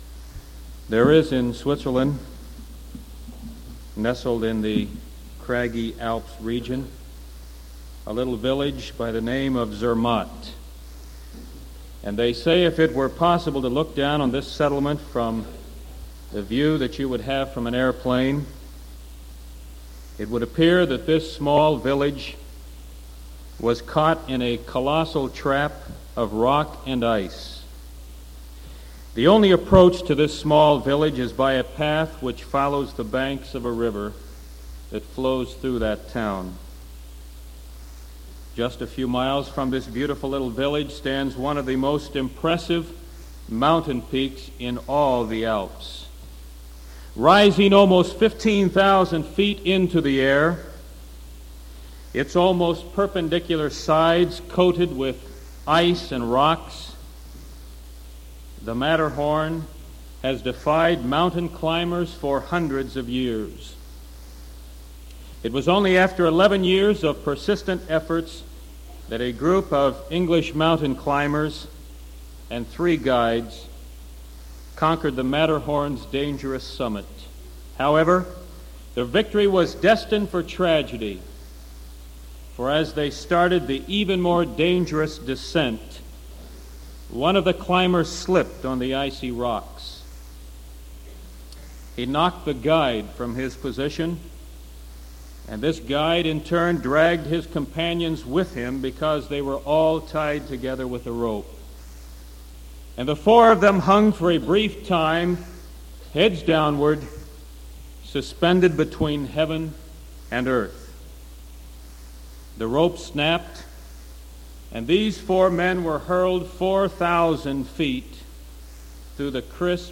Sermon Novemebr 25th 1973 PM (Tape 2)